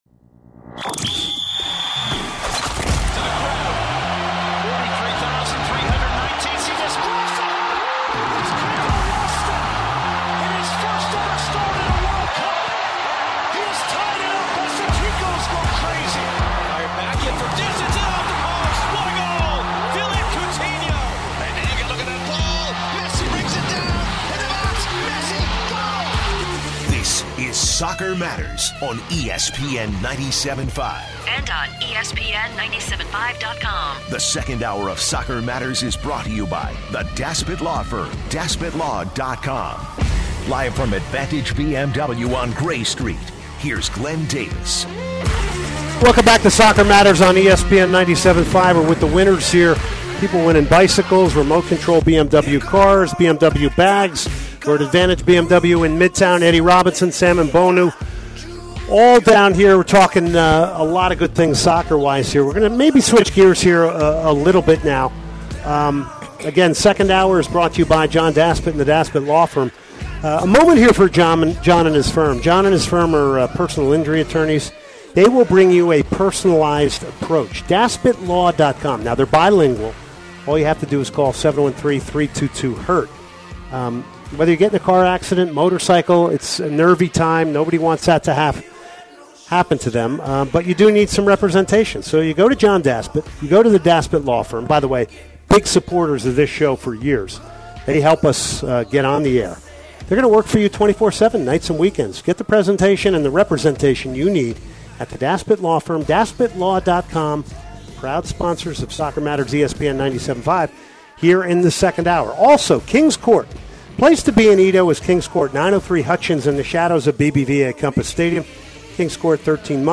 The Guys debate on who should be the next USA National team Manager. Should it be only an American coach affiliated with the MLS?